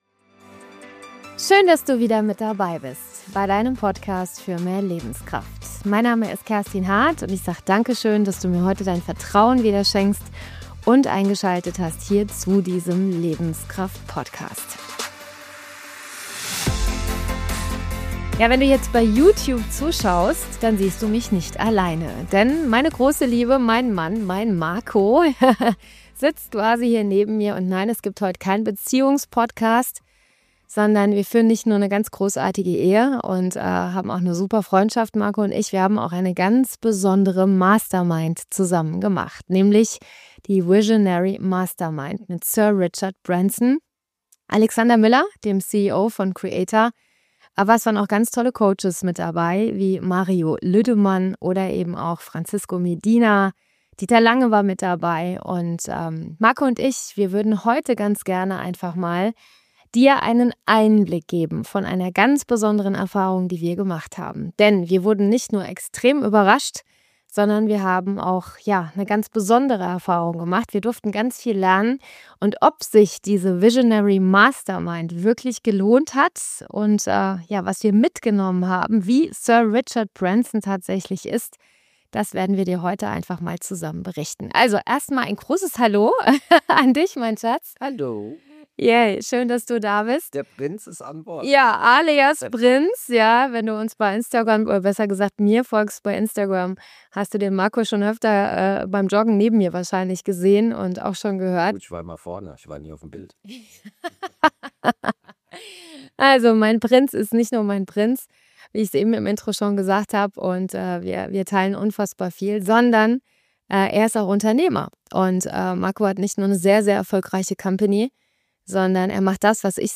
Deep-Talk